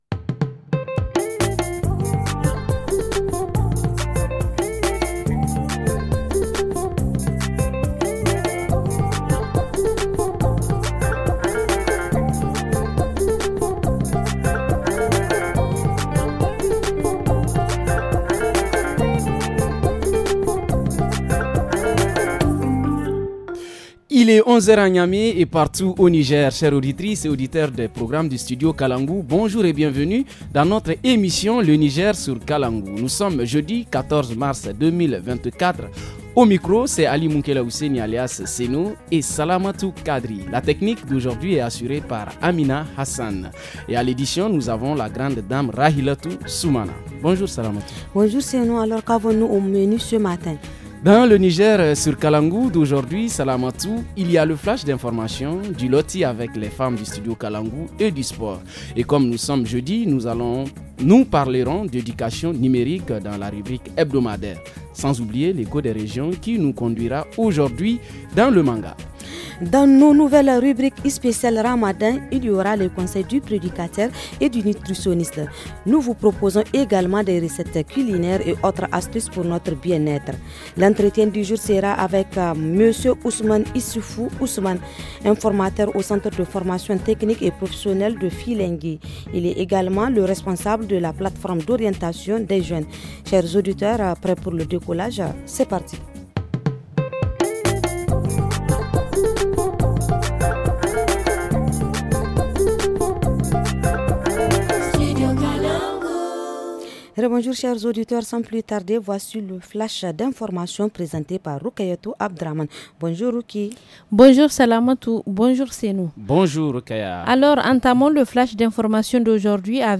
Rubrique hebdomadaire : qu’est-ce qu’une empreinte numérique ? Reportage région : Des cas de « vols », d’organes à Diffa ; Culture linguistique : explication du mot « jeûne » en fulfuldé.